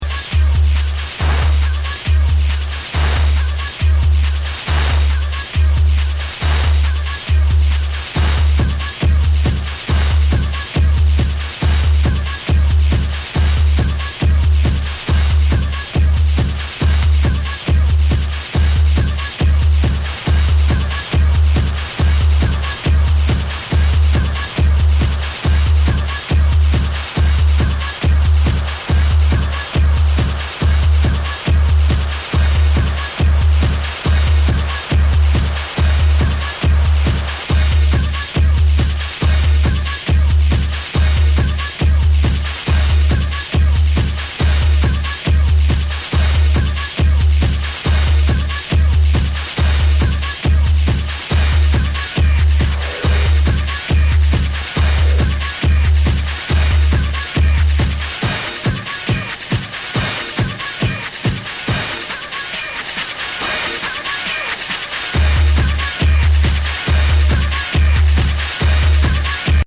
6 pure tracks recorded live on cassette.
Electronix Techno